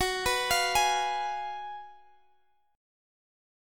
GbM7sus2sus4 Chord (page 2)
Listen to GbM7sus2sus4 strummed